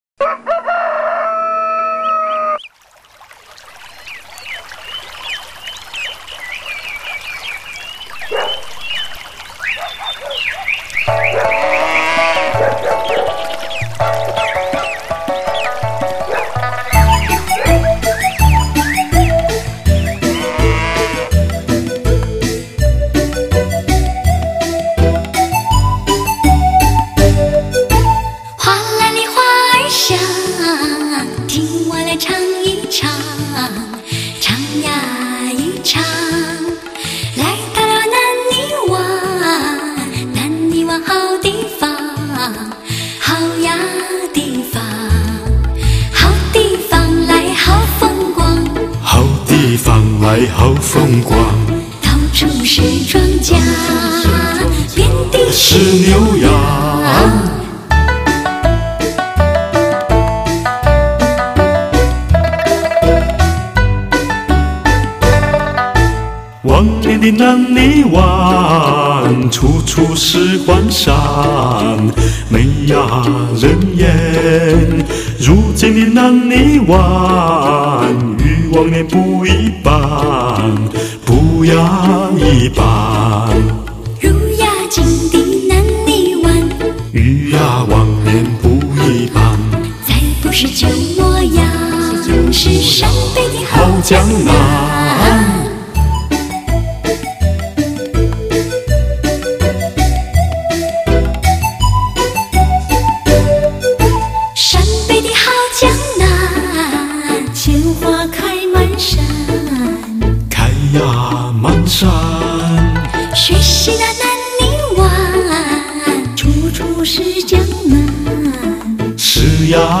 类型: HIFI试音
国内首创，真正按照音乐内容打造极品环绕声测试碟，多点定位人声试音典范，超乎想象顶级享受，高品质录制，典藏之首选。